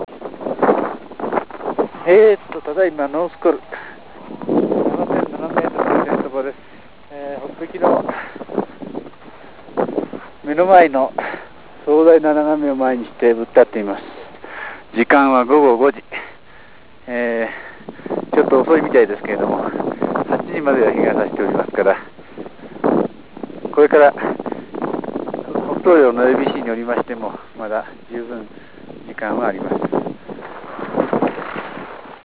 チョモランマ・ノースコルから実況